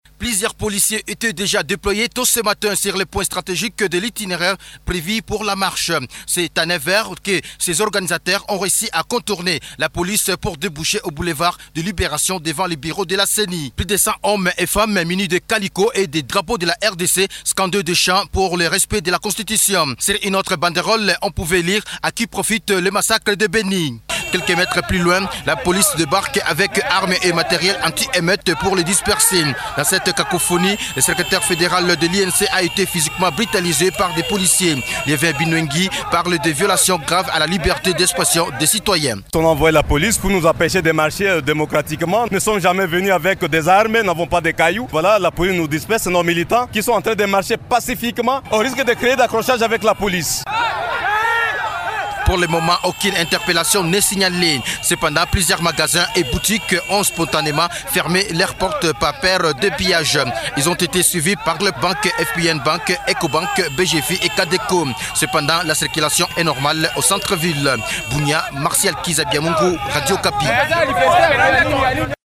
Vous pouvez écouter le reportage de notre reporter sur place.